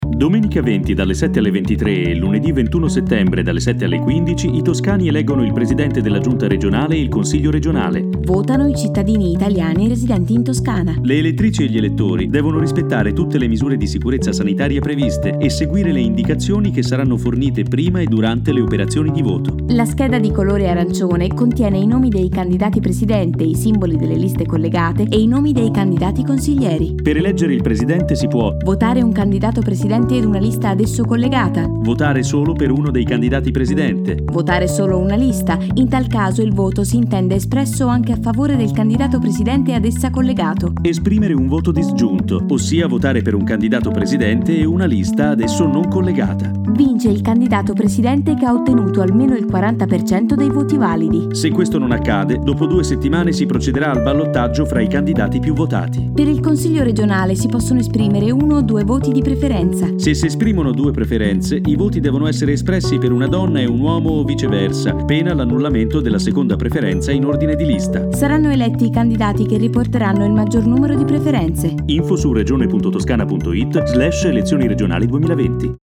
Spot radio elezioni 2020: come si vota